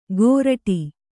♪ gōraṭi